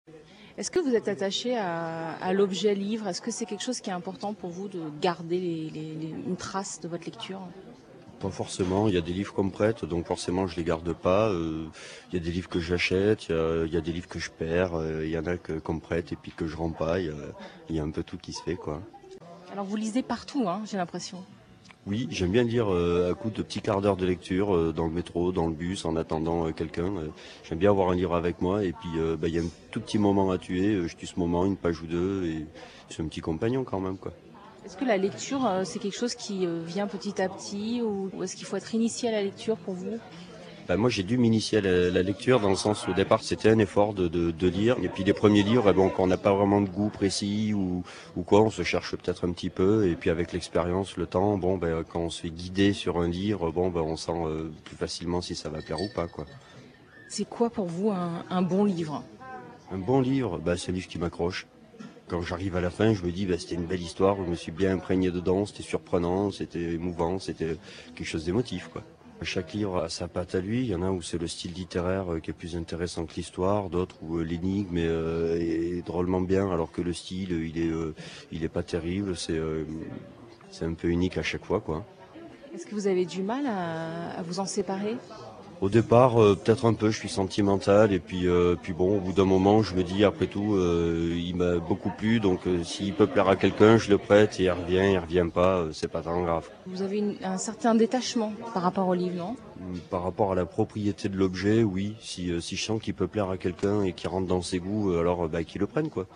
Lecture
Pas de vie sans livres donc, comme le raconte ce lecteur qui ne les a pourtant pas toujours aimés.